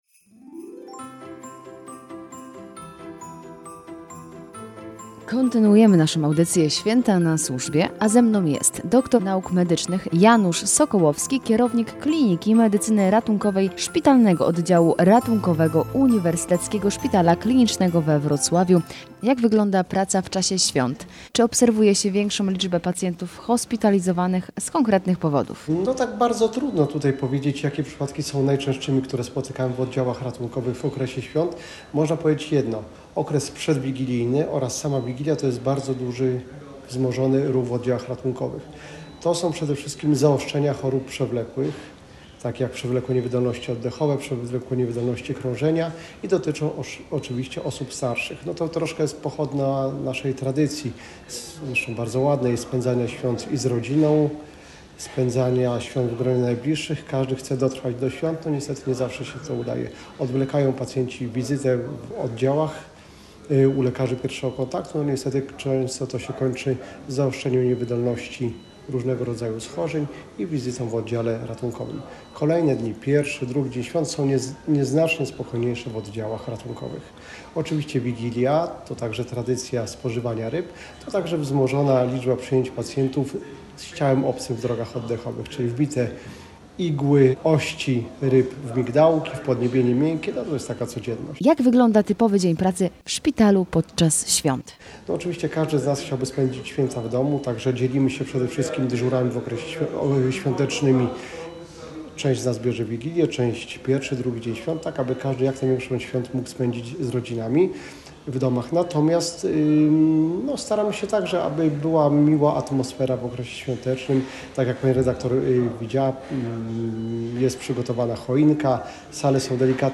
W audycji „Święta na służbie” zaglądamy do Uniwersyteckiego Szpitala Klinicznego we Wrocławiu, aby porozmawiać z lekarzami i pielęgniarkami pracującymi w szpitalu, którzy opowiedzą nam, jak spędzają święta w pracy? Czy czas świąt wpływa na atmosferę?